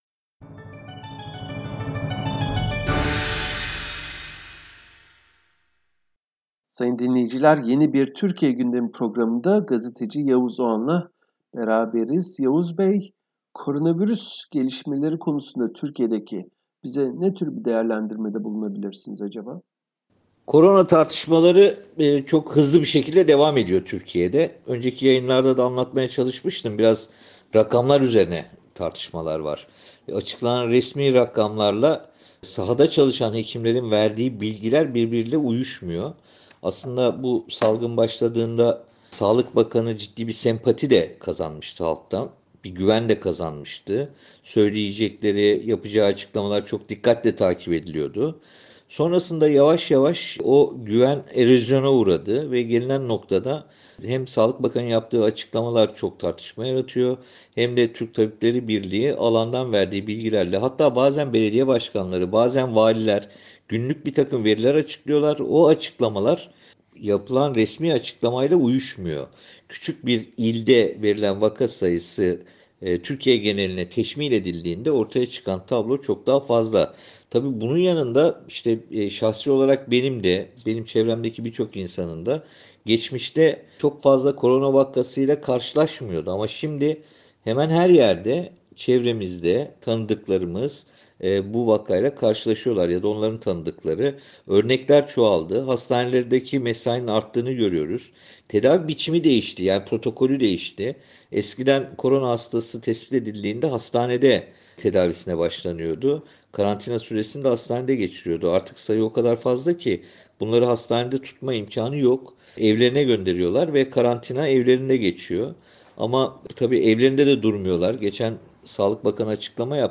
Gazeteci Yavuz Oğhan, SBS Türkçe'ye verdiği röportajda, Türkiye'de koronavirüsle mücadelede artan vakaların ve ölümler endişe yarattığını söyledi.